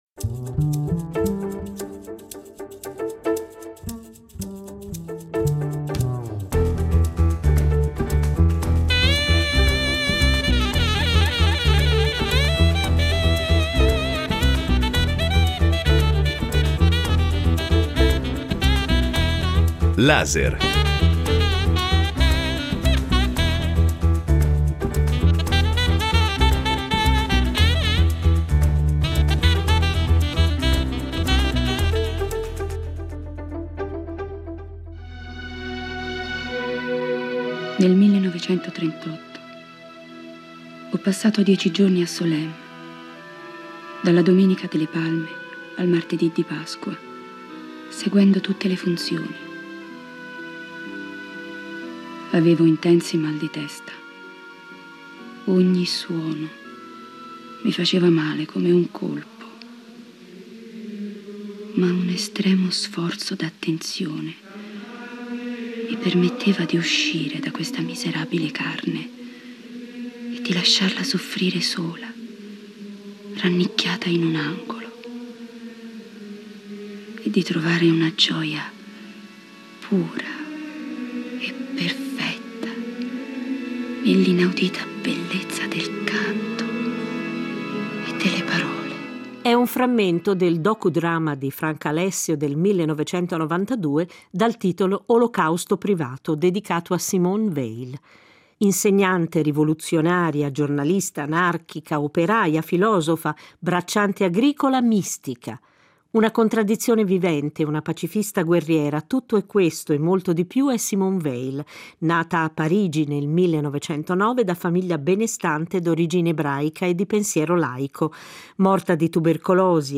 Cogliamo qualcuno degli aspetti del suo pensiero in un’intervista